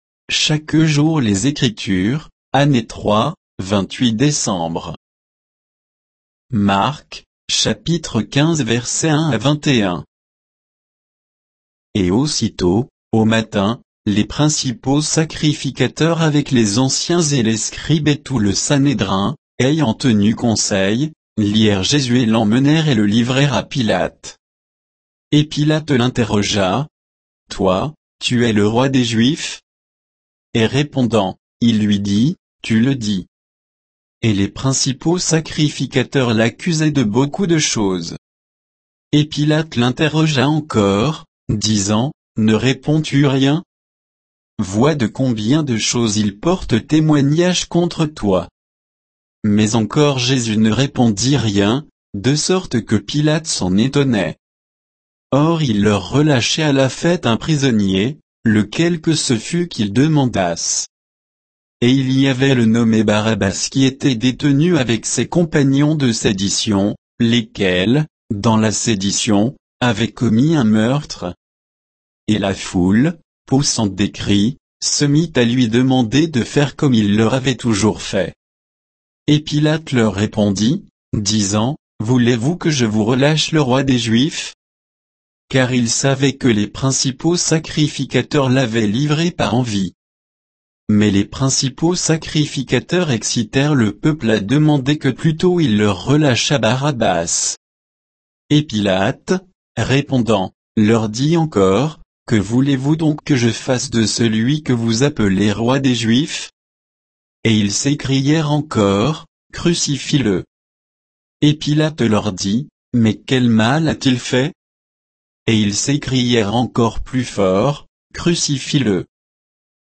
Méditation quoditienne de Chaque jour les Écritures sur Marc 15